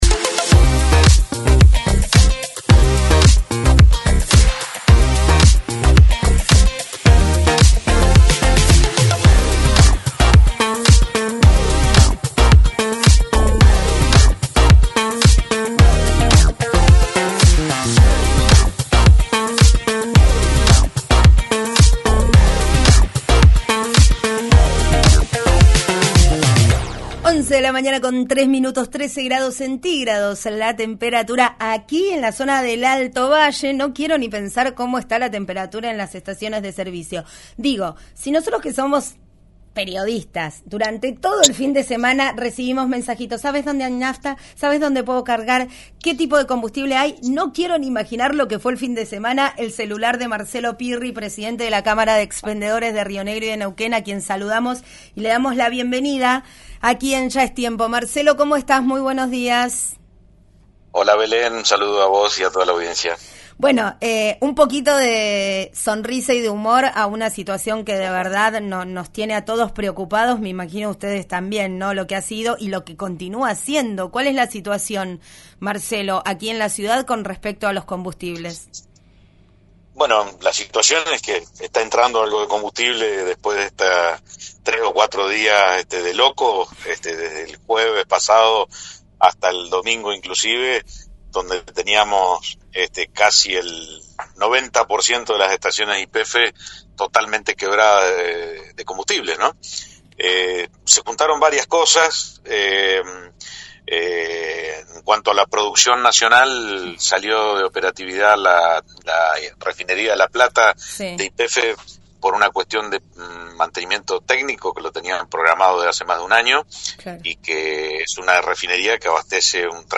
«Se juntaron varias cosas. Por un lado, salió de operación parte de la refinería de La Plata de YPF por mantenimiento técnico. Lo tenían programado desde hace más de un año y es una refinería que abastece el 30% del mercado nacional de YPF. La compañía lo tenía previsto con una importación de seis buques pero quedaron parados en el puerto de La Plata a la espera de que el gobierno nacional liberara los dólares para esa importación», explicó en diálogo con «Ya Es Tiempo» de RÍO NEGRO RADIO.